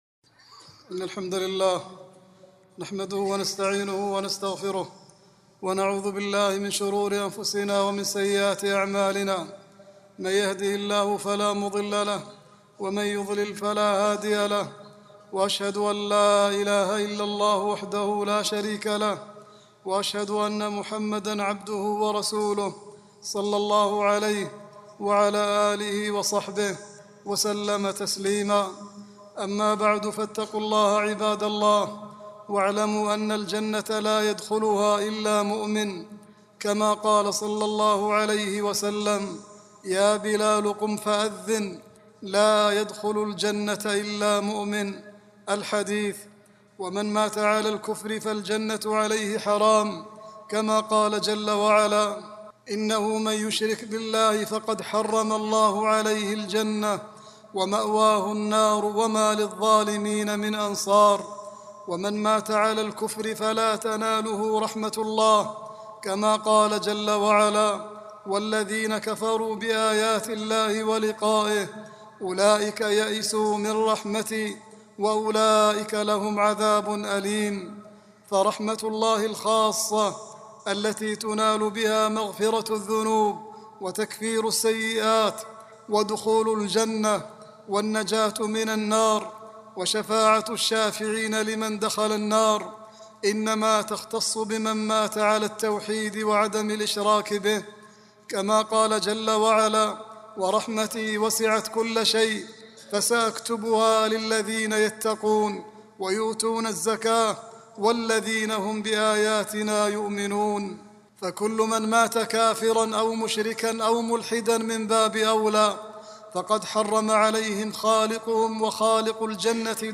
العنوان : تحريم الاستغفار والترحم على من مات يعبد غير الله أو يكفر به خطبة
khutbah-28-6-39.mp3